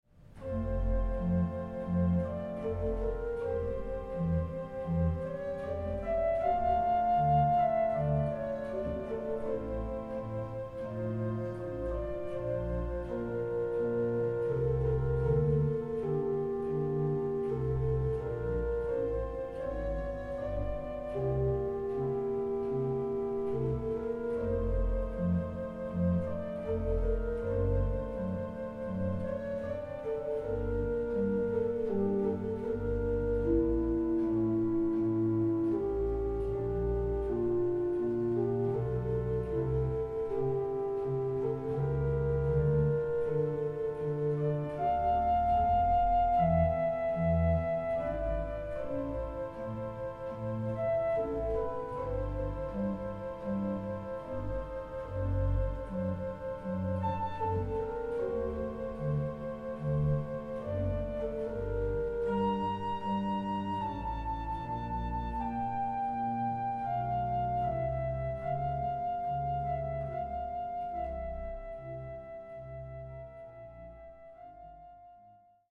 Konzert CD
WALCKER-Orgel von 1928.
Adagio